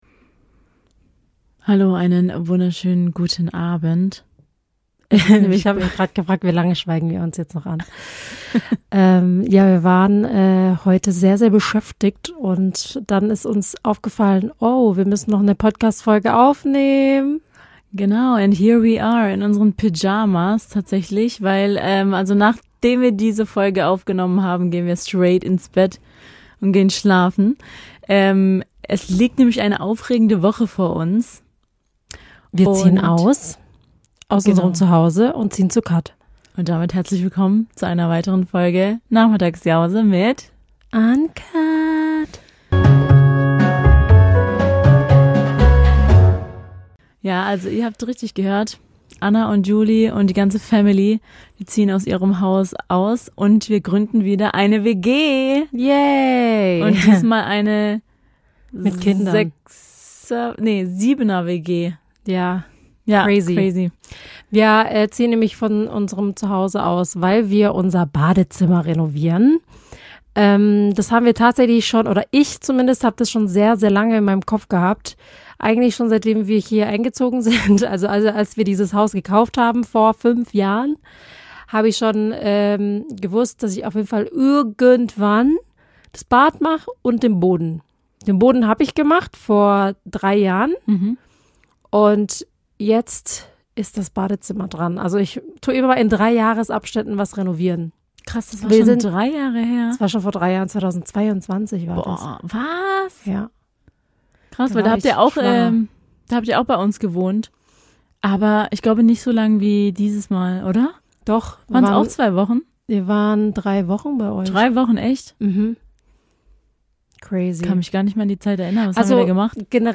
Wir sind zwei Schwestern, die sich gerne mal bei einem Kaffee und Kuchen unterhalten und über jeden Klatsch und Tratsch reden.